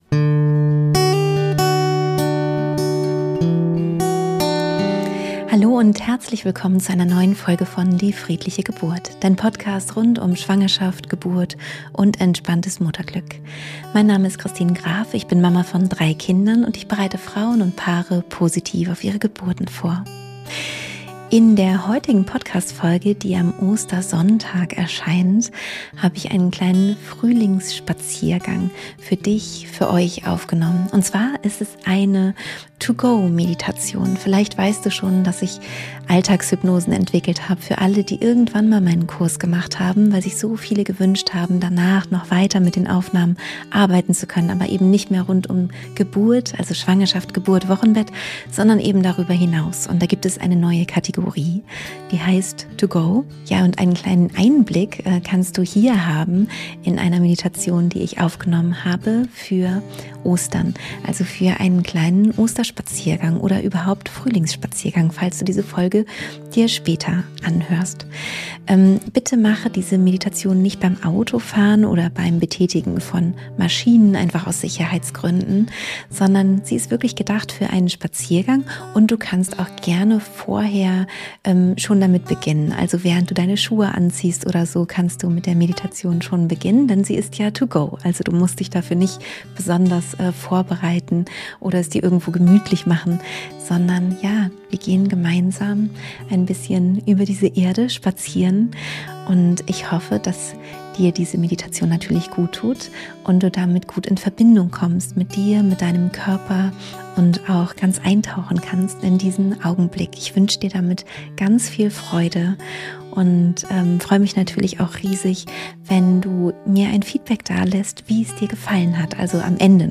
In dieser Podcastfolge gibts eine taufrische Meditation to go, die du auf einem Spaziergang hören, kurz bei dir einchecken und dabei in der Natur doppelt entspannen kannst.